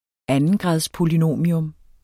Udtale [ ˈanəngʁɑðs- ]